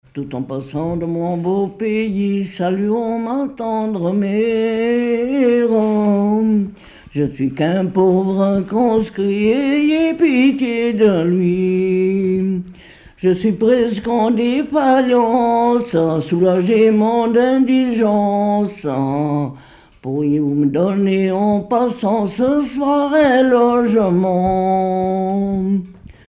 Mémoires et Patrimoines vivants - RaddO est une base de données d'archives iconographiques et sonores.
Pièce musicale inédite